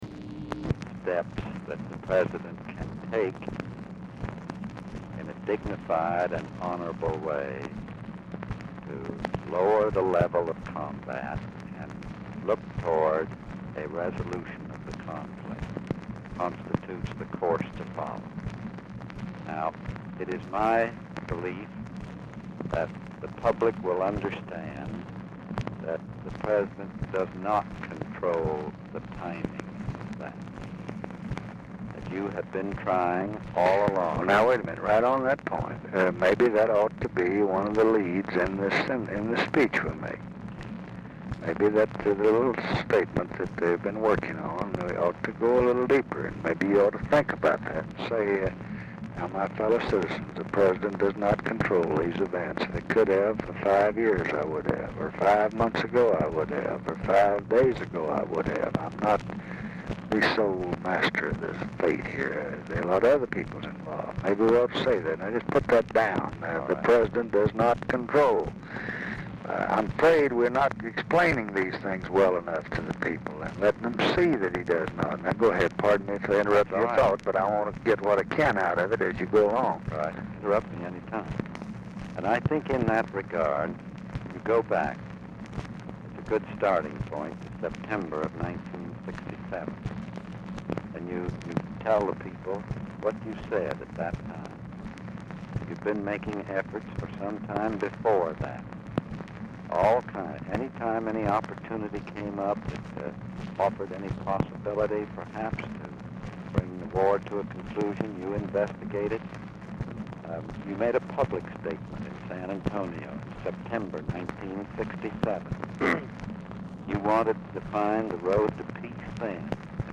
Telephone conversation # 13570, sound recording, LBJ and CLARK CLIFFORD, 10/22/1968, 5:24PM
RECORDING STARTS AFTER CONVERSATION HAS BEGUN; CONTINUES WITH SOME INTERRUPTION ON NEXT RECORDING
Format Dictation belt